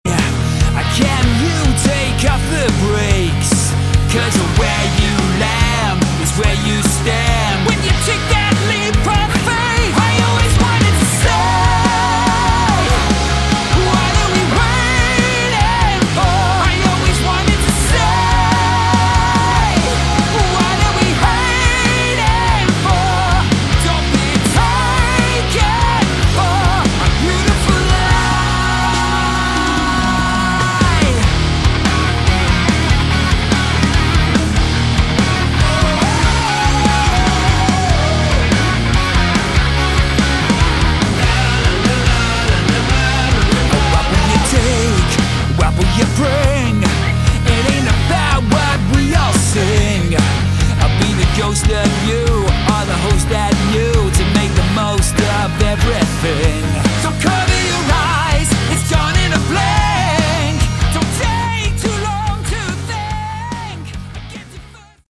Category: Melodic Rock
vocals
bass
keyboards
guitars
drums